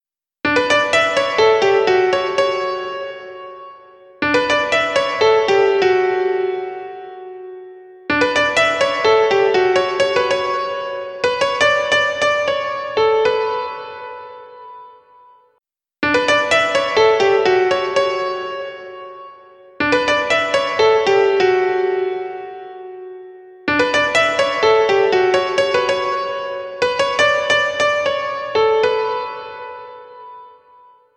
забавные
без слов
инструментальные
пианино
простые
одним пальцем на пианине...